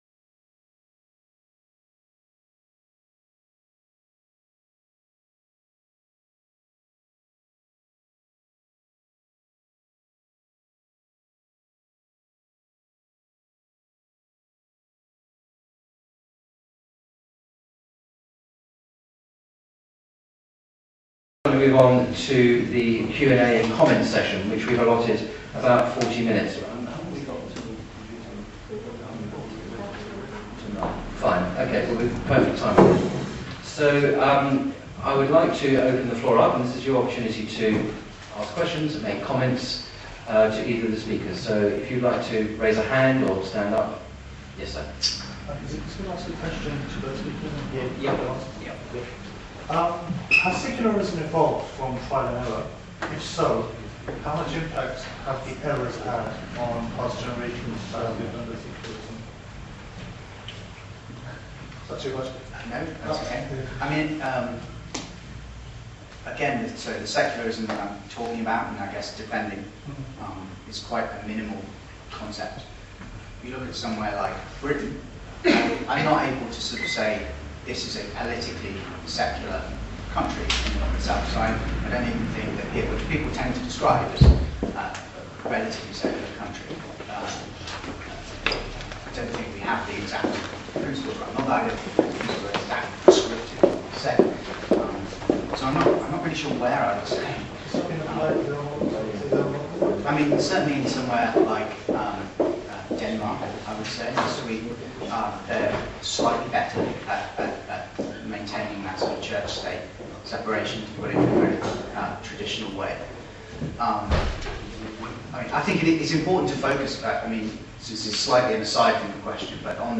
Atheist vs Muslim Debate： Secularism vs Political Theism (Part 2： Q⧸A).mp3